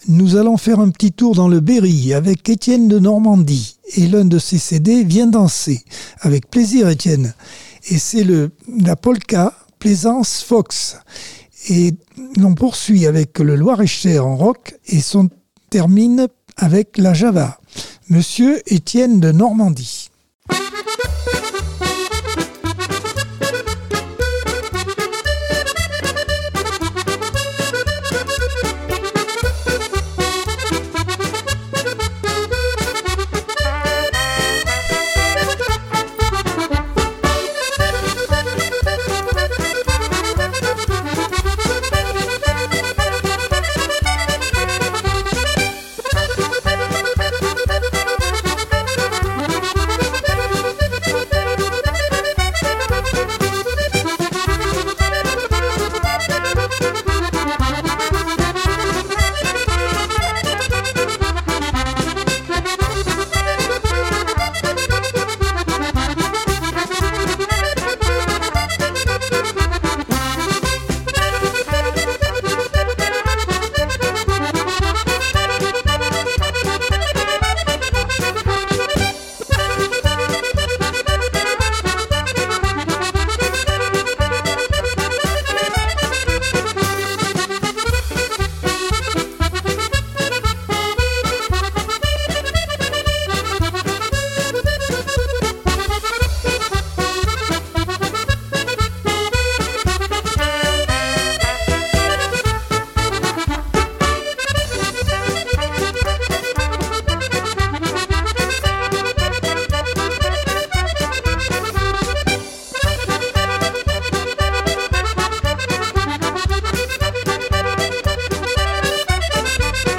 Accordeon 2024 sem 38 bloc 2 - Radio ACX